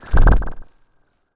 sci-fi_small_spaceship_jet_blast_01.wav